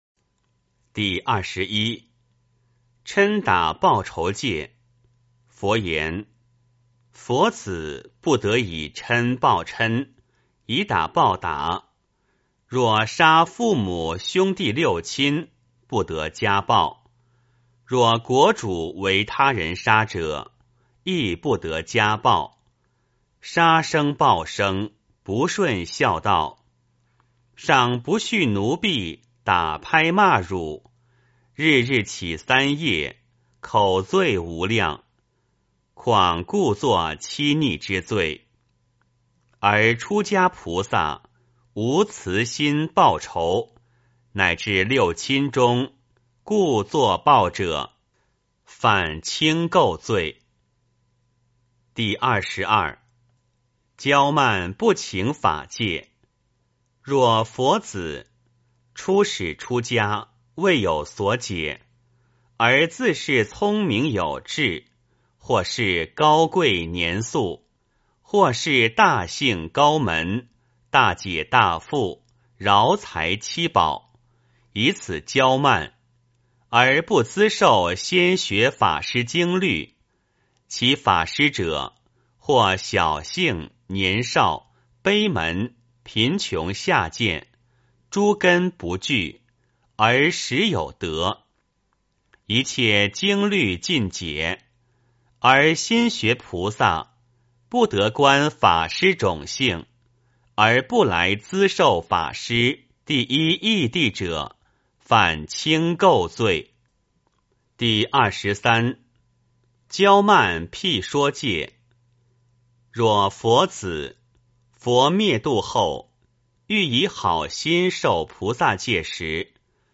梵网经-四十八轻戒21 - 诵经 - 云佛论坛